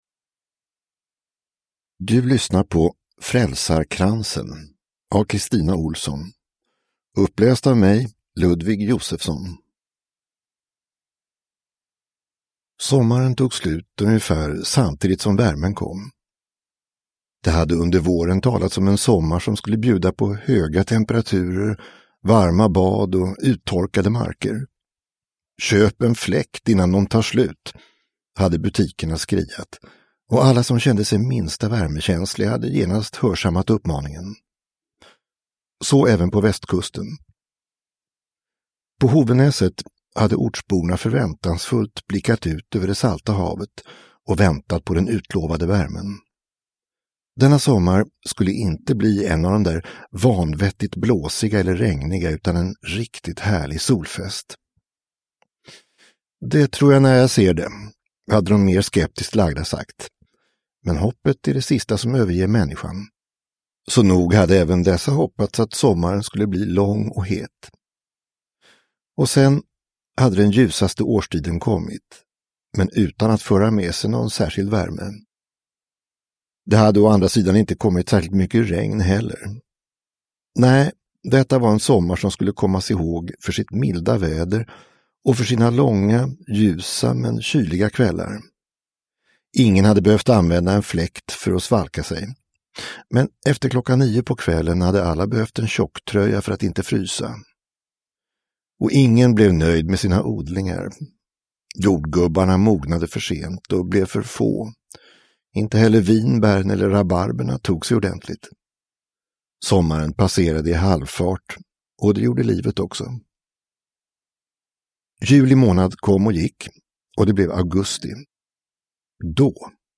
Frälsarkransen (ljudbok) av Kristina Ohlsson